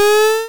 electric_reload.wav